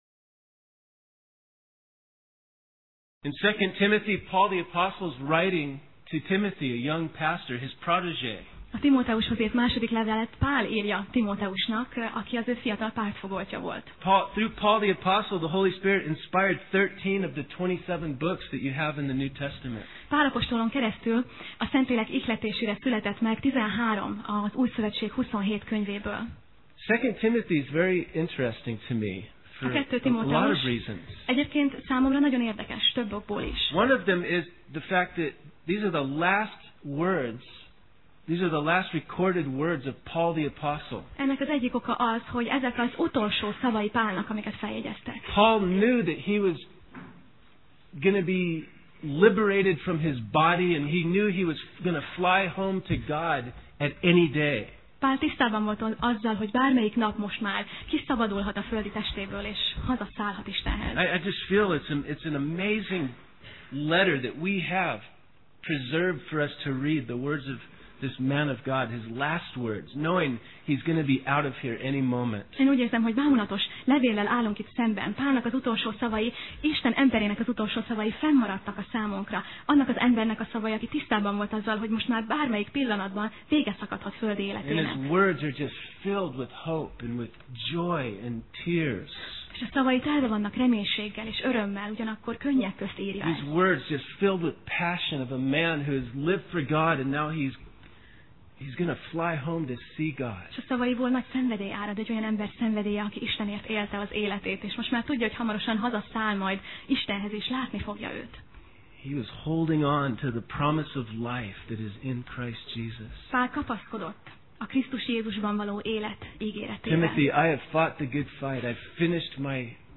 Sorozat: Tematikus tanítás Alkalom: Vasárnap Reggel